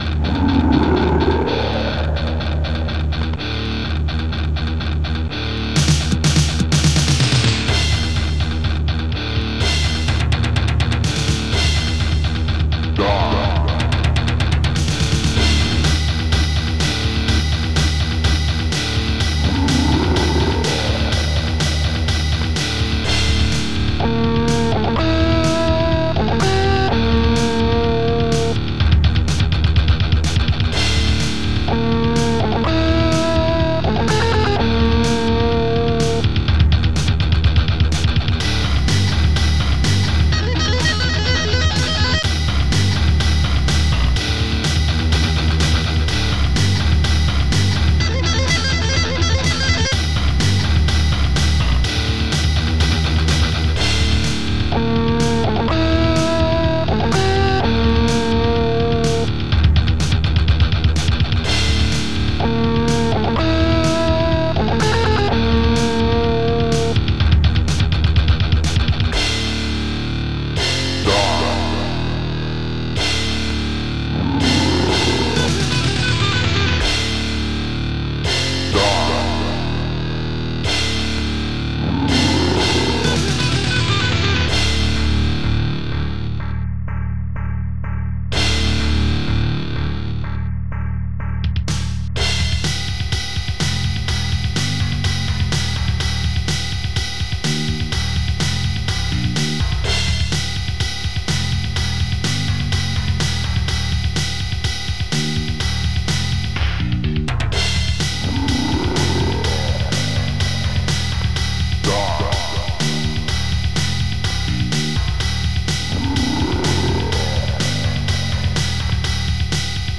Metal and Grunge MODS